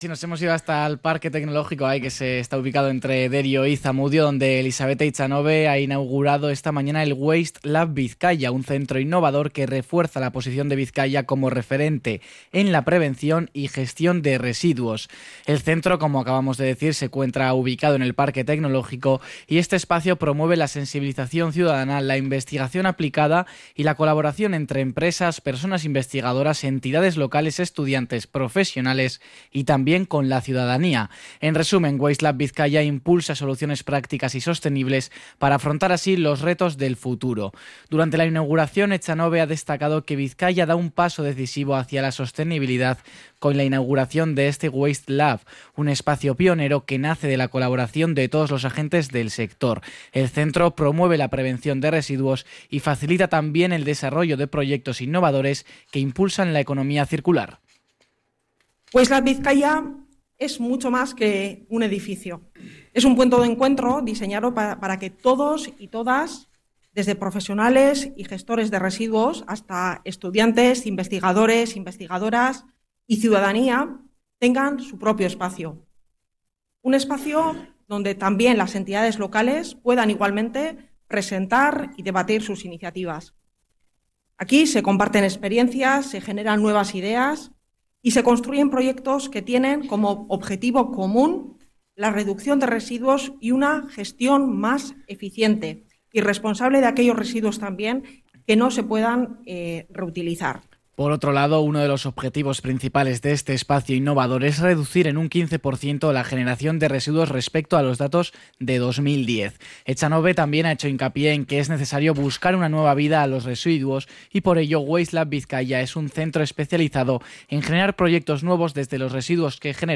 Crónica de la presentación de Waste Lab Bizkaia en el Parque Tecnológico